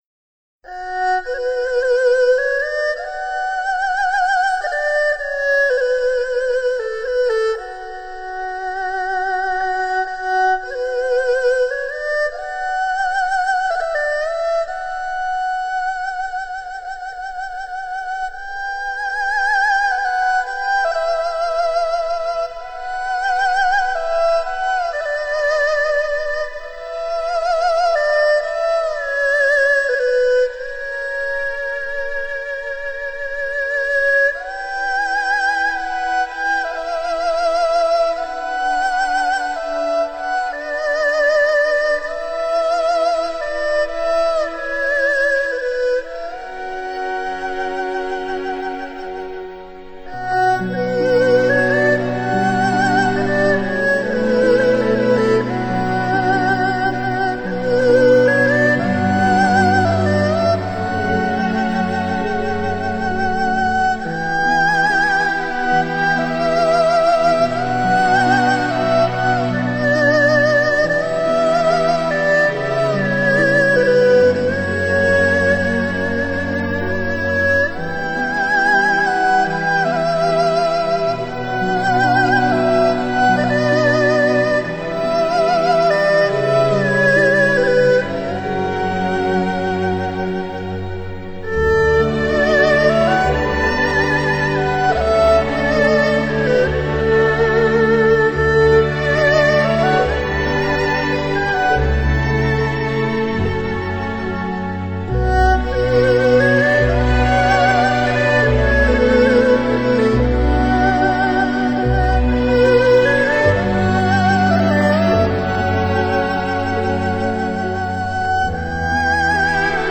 有各种不同的乐器，管弦乐、电吉、塔布拉-巴亚以及钢琴等作为协奏。使二胡产生了比以往更具感染力的音质。